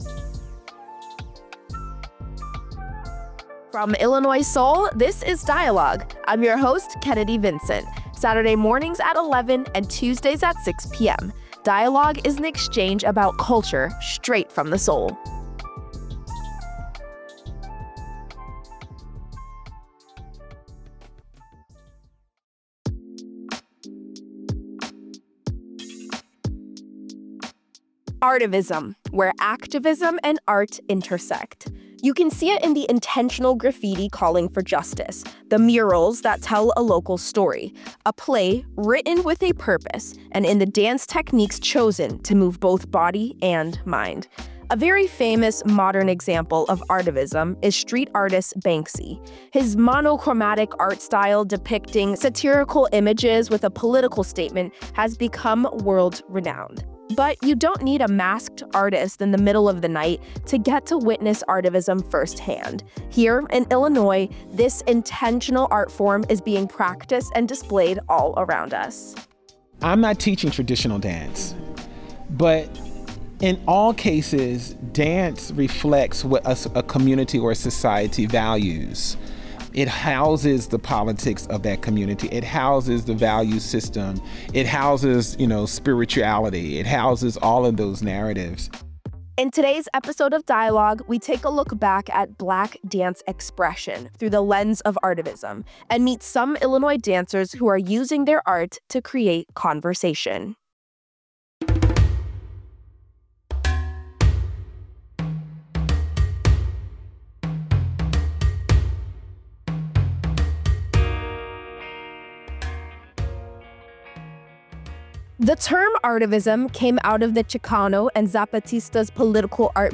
In this segment, hear from two Illinois dancers who are using their art to create conversation.